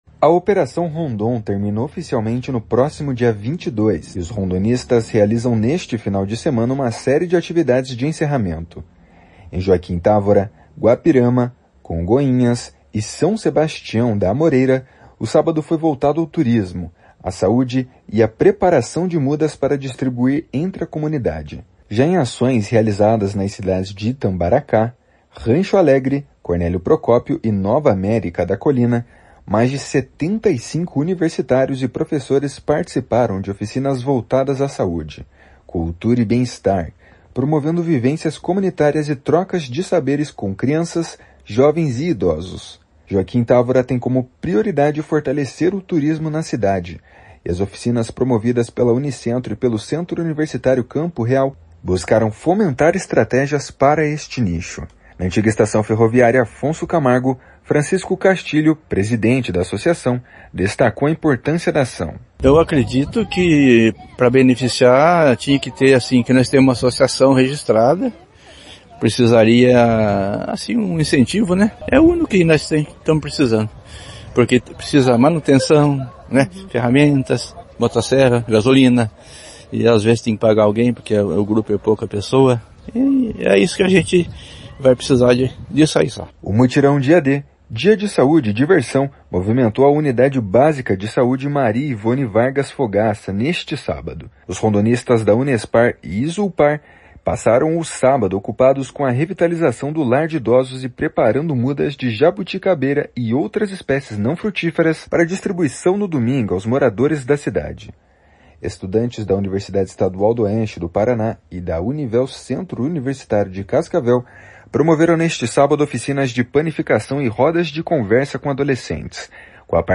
A Operação Rondon Paraná 2025 acontece no Norte Pioneiro entre 10 e 22 de julho e reúne 360 estudantes e professores de universidades estaduais e privadas do Paraná. (Repórter